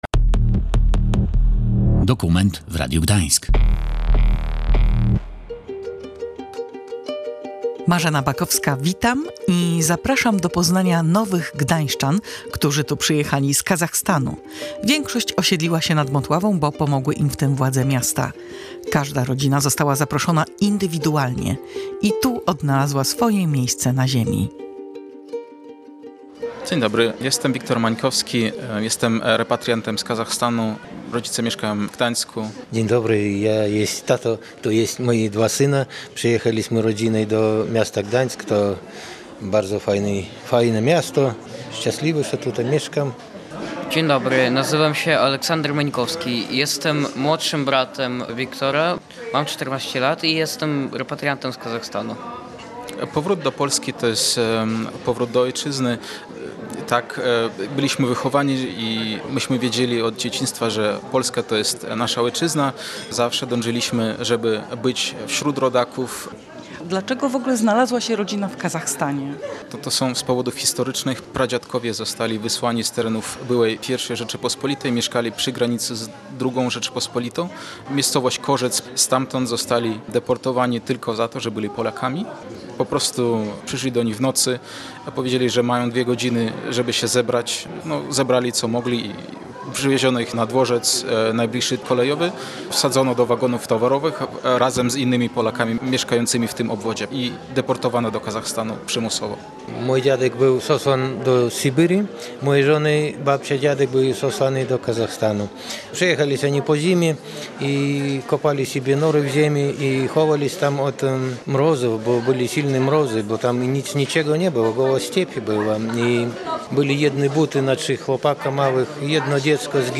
Bohaterami audycji są urodzeni w Kazachstanie, którzy skorzystali z pomocy dla repatriantów. Opowiadają o trudnych losach swych przodków, ale przede wszystkim o tym, jak odnaleźli się w nowej rzeczywistości.